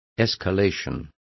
Also find out how escalada is pronounced correctly.